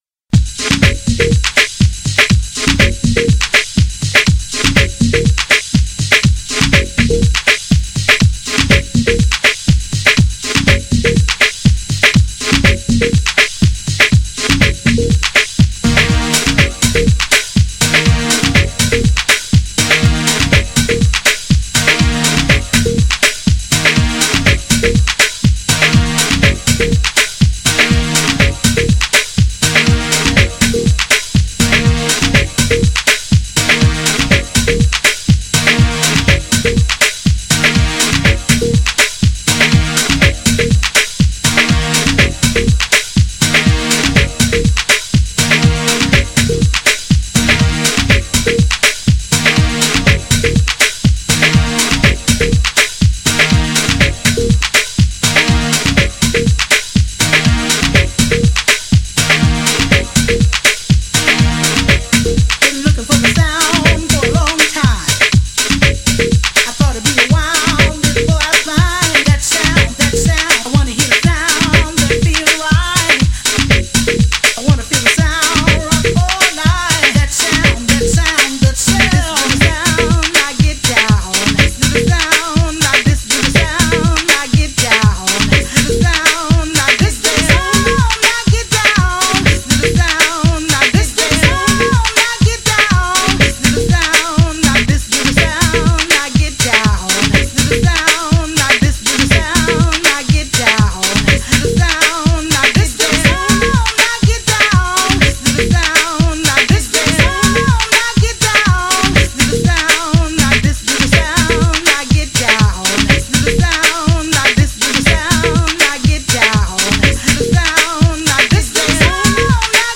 GENRE House
BPM 121〜125BPM